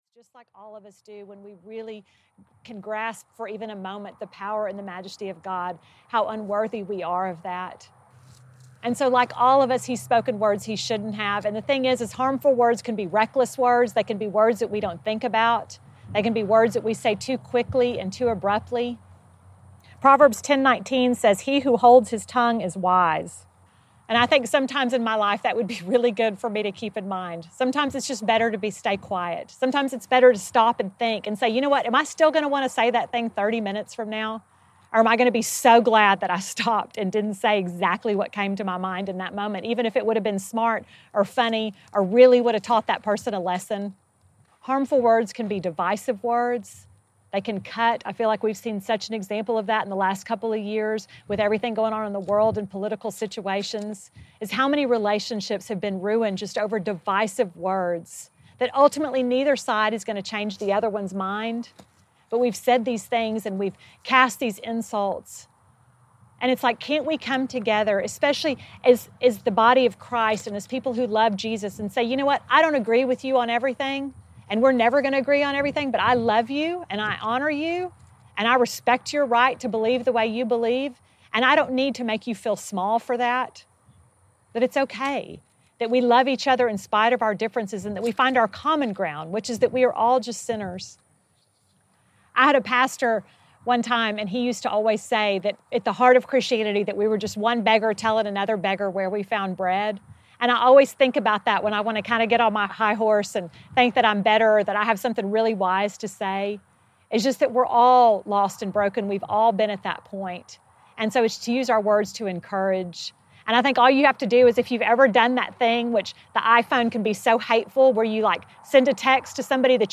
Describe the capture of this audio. These sessions will reflect the ambiance of the unique recording locations, immersing the listener into the teaching.